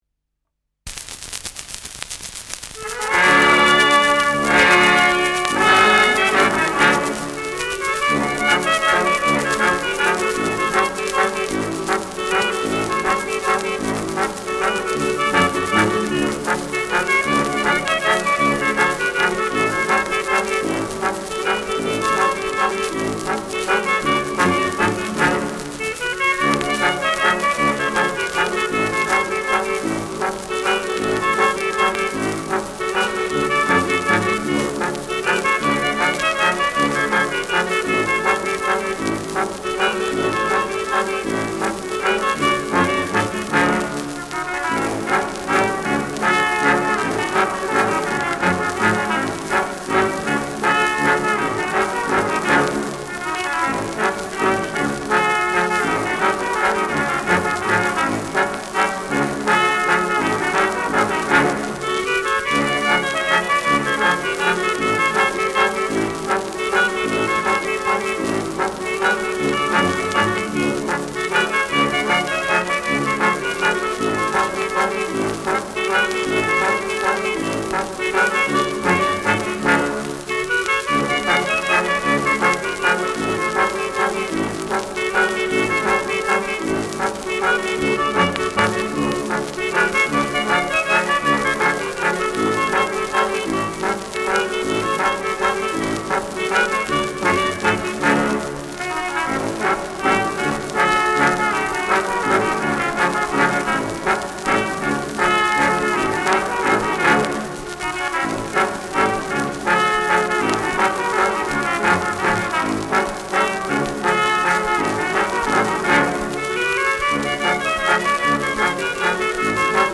Schellackplatte
leichtes Rauschen
Kapelle Jais (Interpretation)
[München] (Aufnahmeort)